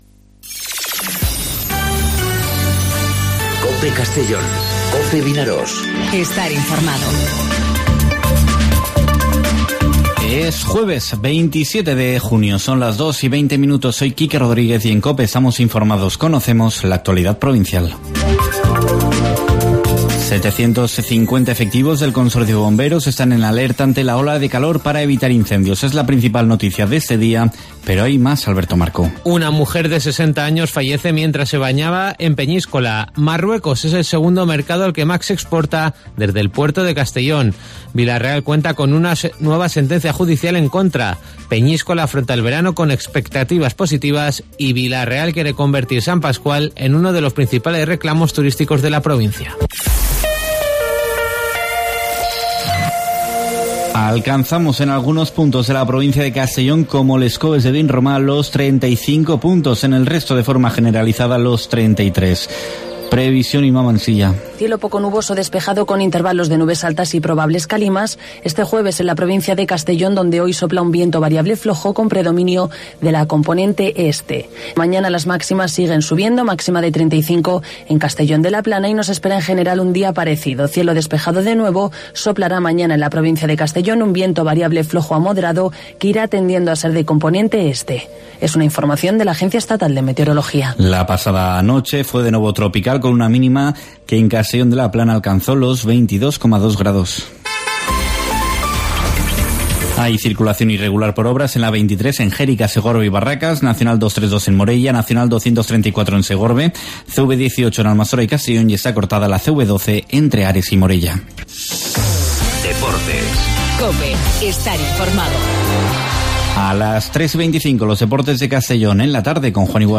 Informativo 'Mediodía COPE' en Castellón (27/06/2019)